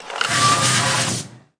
Wep Vacuum Launch Sound Effect
Download a high-quality wep vacuum launch sound effect.
wep-vacuum-launch.mp3